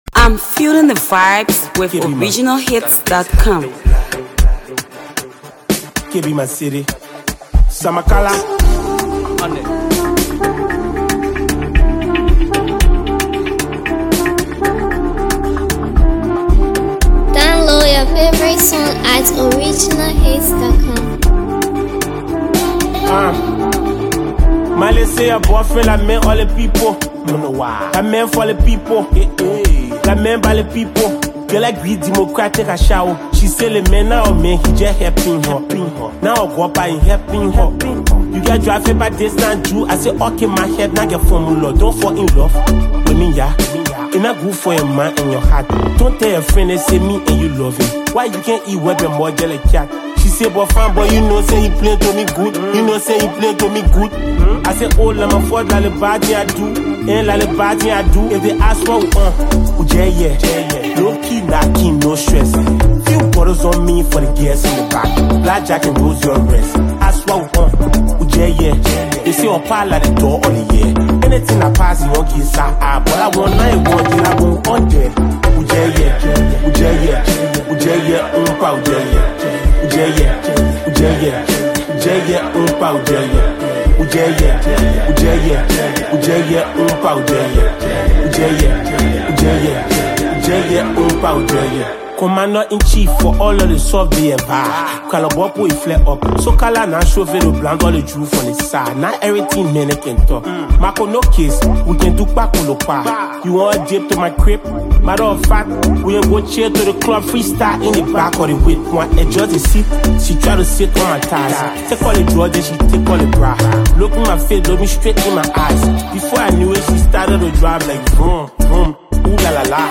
Talented Liberian rapper